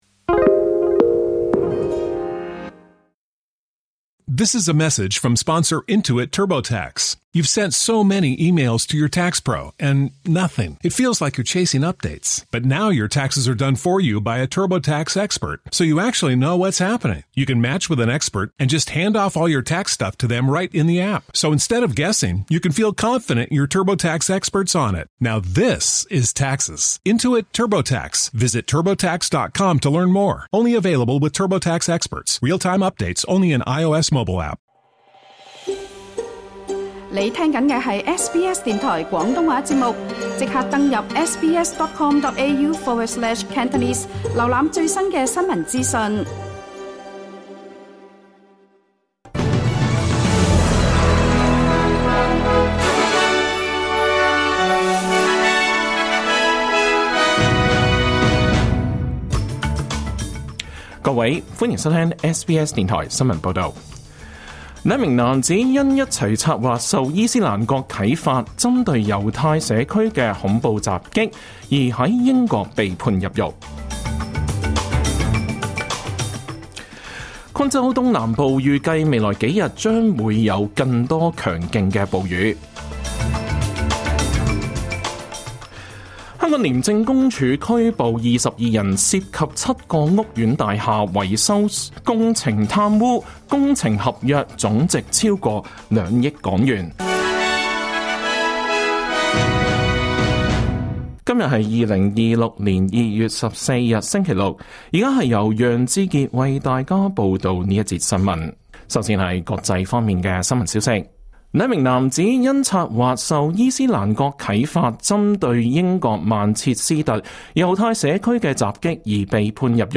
2026 年 2 月 14 日 SBS 廣東話節目詳盡早晨新聞報道。